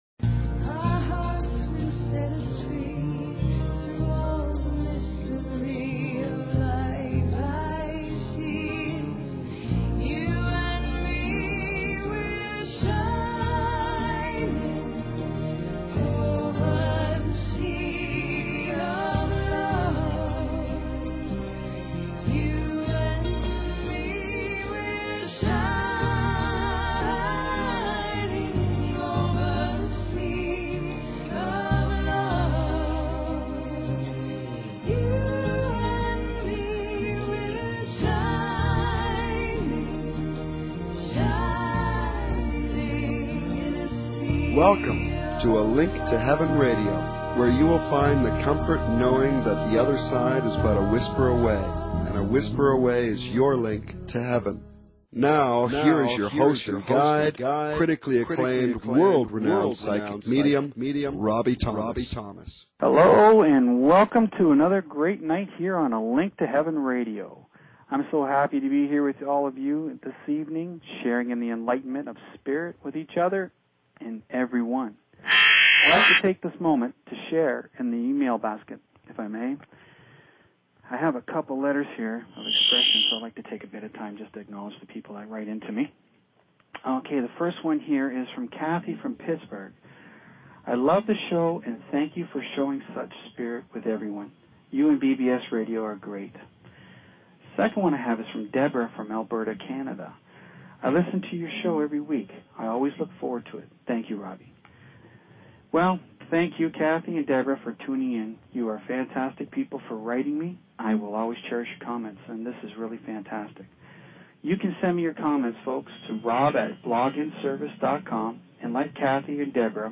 Free Psychic Readings for call-ins